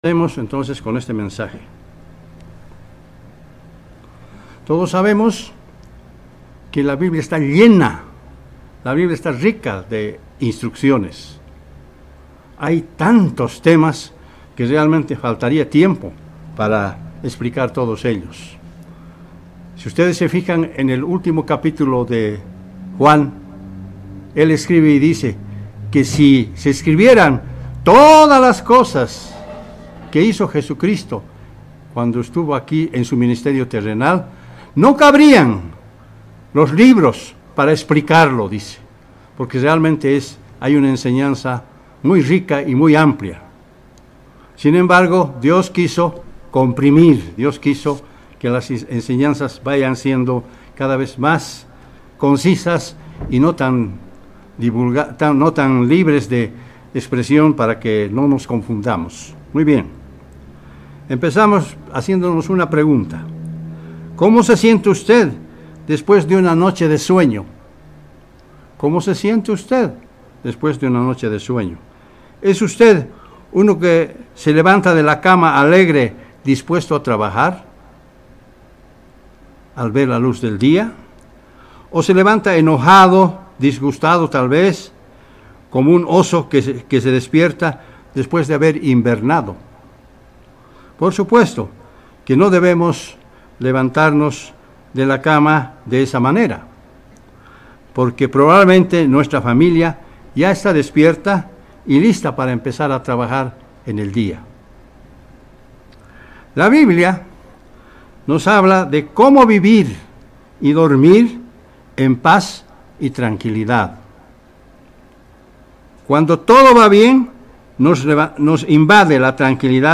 Mensaje entregado el 13 de agosto de 2022.
Given in La Paz